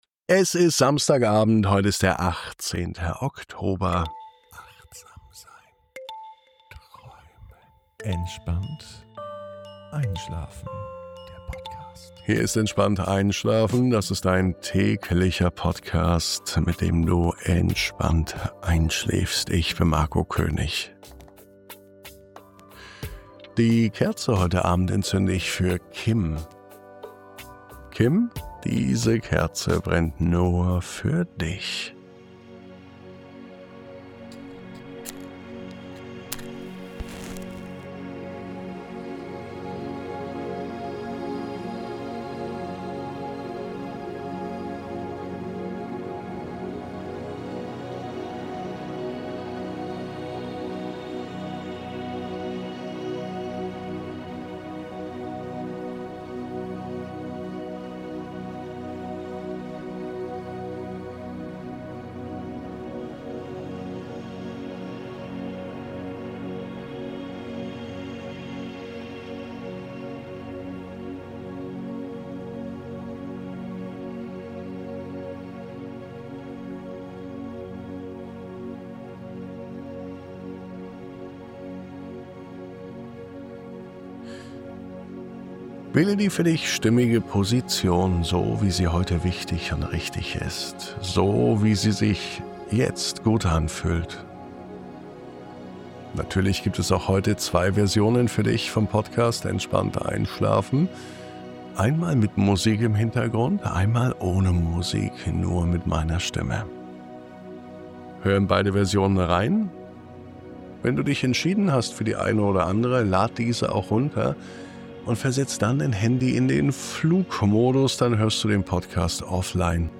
Diese Traumreise lädt dich ein, deinen Atem mit beruhigenden Wörtern zu verknüpfen – sanft, im eigenen Rhythmus.